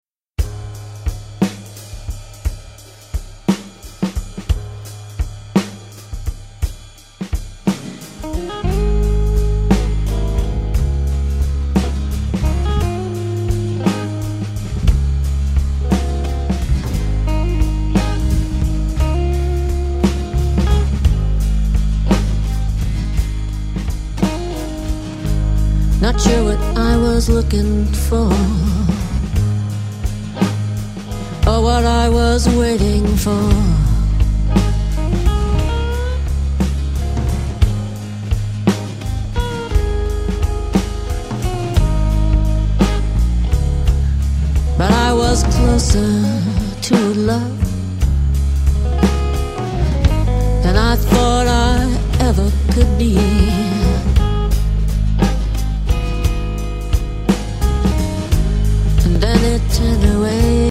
Native American Flute & Folk Music